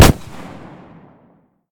pistol-shot-05.ogg